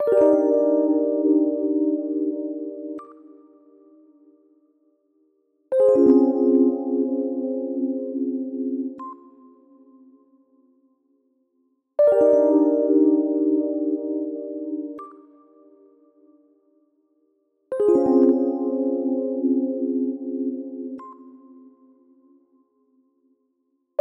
Треск динамиков rhodes
В наушниках слышен треск, на мониторах слышно тоже.